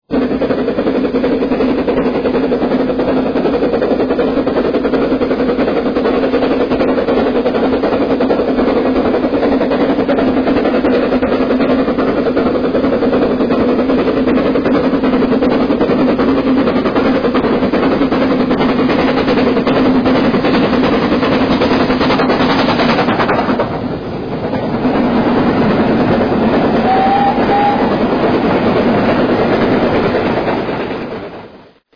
Sounds of British Railways steam locomotives